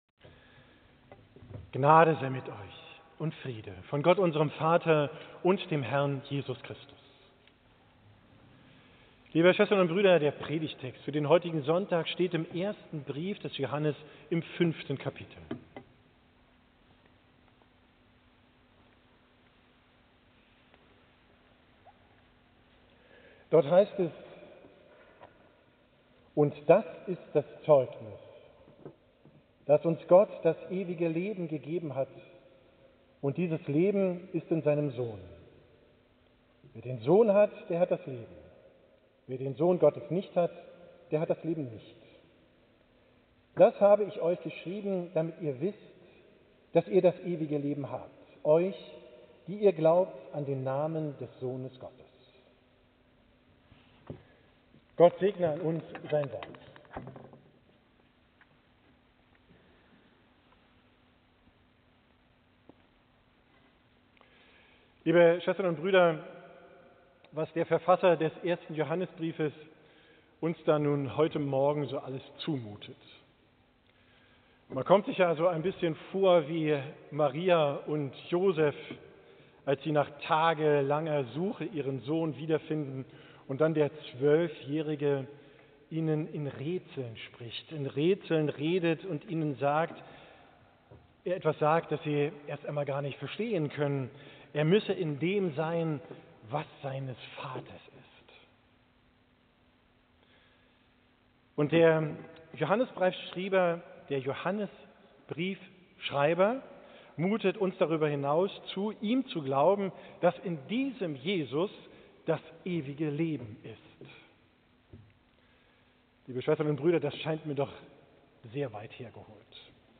Predigttext 1. Johannes 5: 11 Und das ist das Zeugnis, dass uns Gott das ewige Leben gegeben hat, und dieses Leben ist in seinem Sohn. 12 Wer den Sohn hat, der hat das Leben; wer den Sohn Gottes nicht hat, der hat das Leben nicht. 13 Das habe ich euch geschrieben, damit ihr wisst, dass ihr das ewige Leben habt, die ihr glaubt an den Namen des Sohnes Gottes.